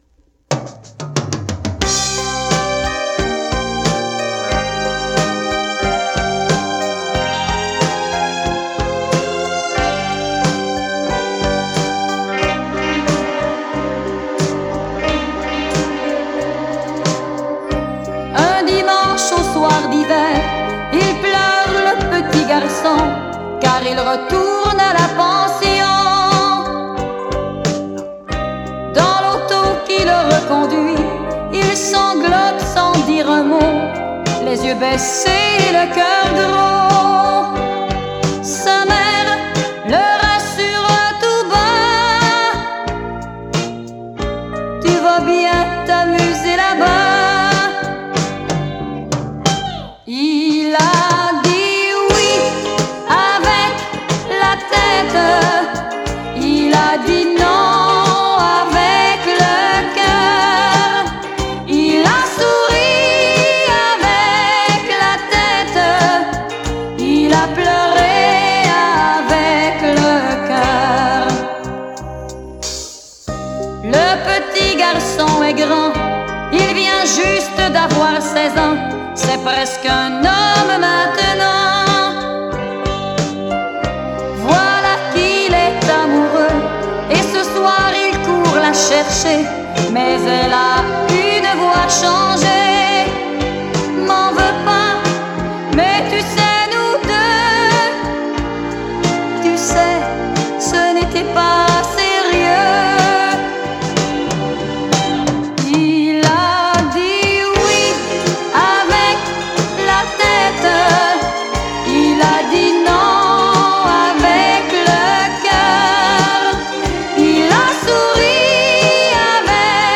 Son: stéréo
Enregistrement: Studio St-Charles à Longueuil